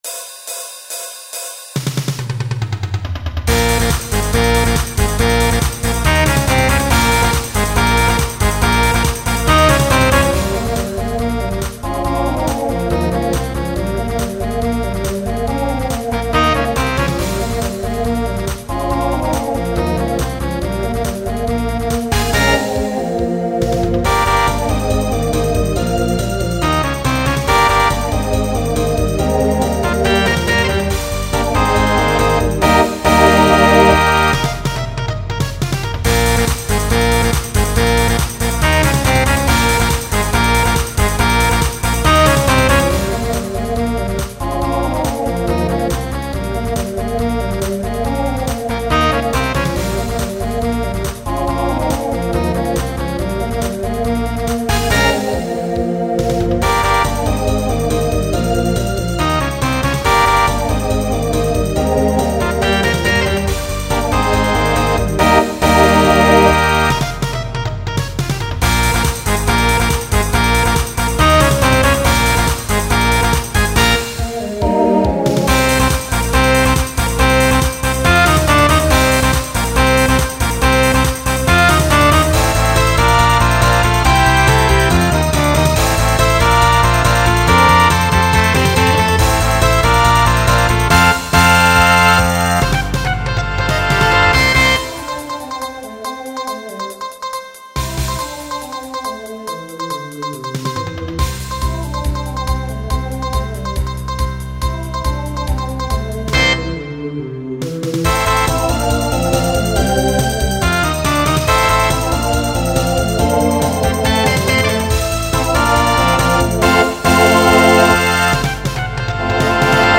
Rock
Transition Voicing TTB